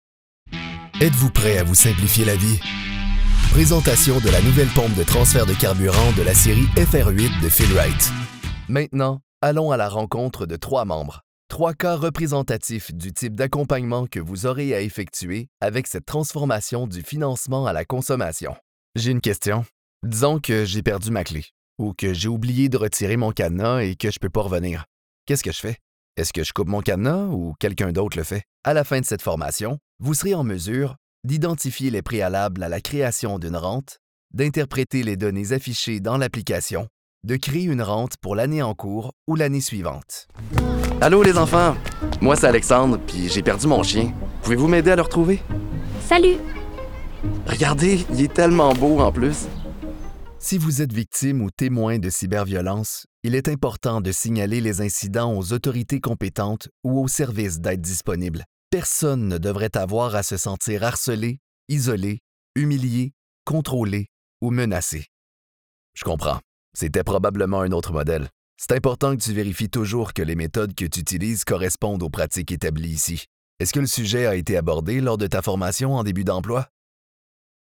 Male
Are you looking for a warm, vibrant, calm, and charming voice? Or do you prefer energy and intensity?
E-Learning
Words that describe my voice are radio, authentic, versatile.